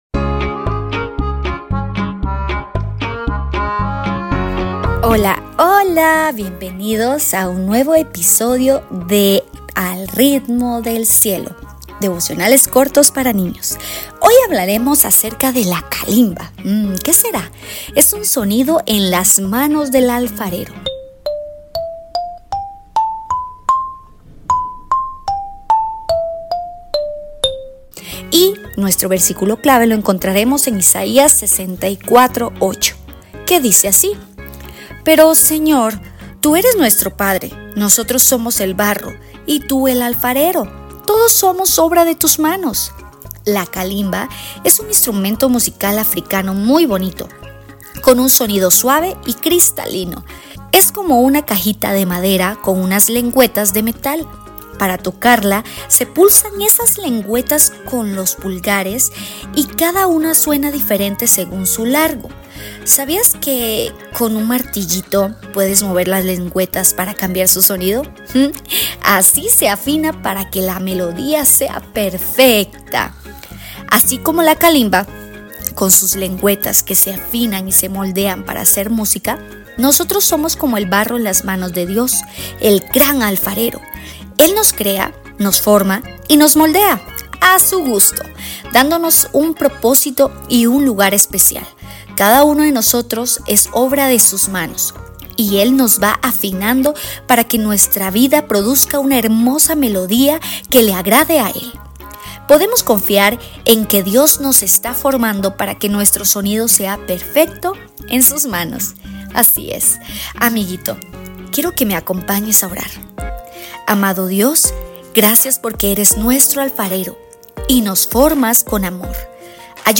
¡Al Ritmo del Cielo! – Devocionales para Niños